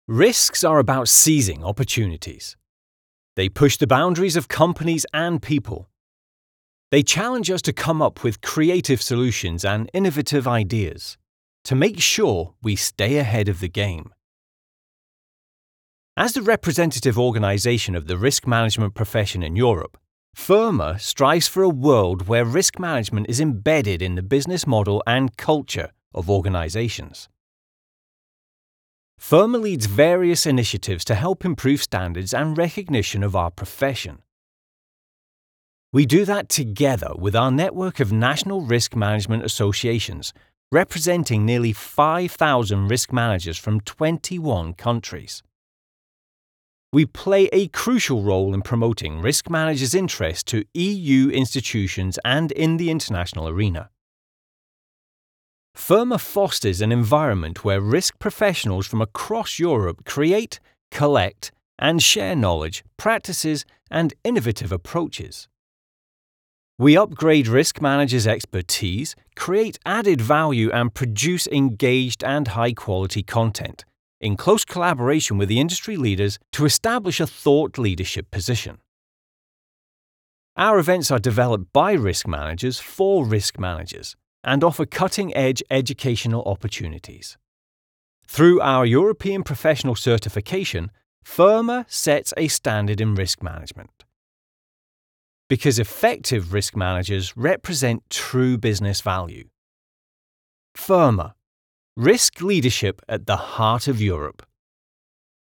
I loved the crisp full sound it produced.